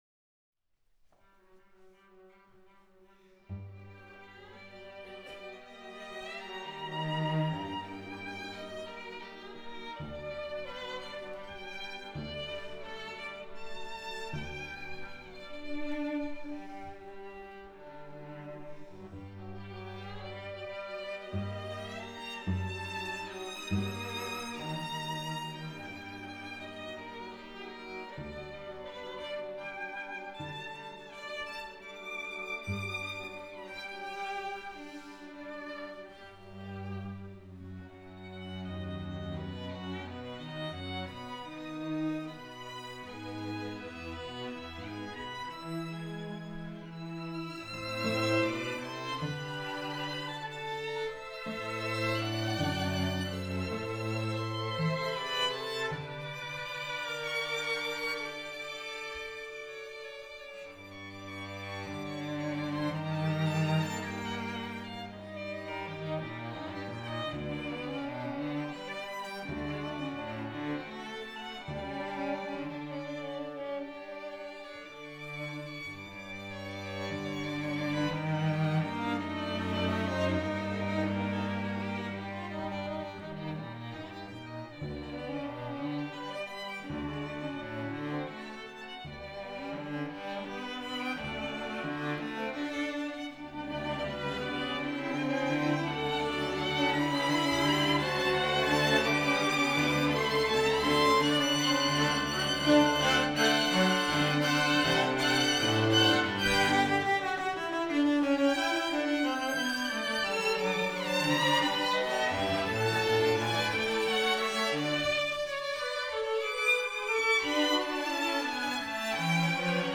String Sextet No 2 in G major Op.36 [1864-5] Allegro non troppo Scherzo: Allegro non troppo – Trio: Presto giocoso Adagio Poco Allegro Brahms’ love life is spelt out in his music for all to hear.
Venue: Bantry House
Instrumentation: 2vn, 2va, 2vc Instrumentation Category:String Sextet
violin
Viola
Cello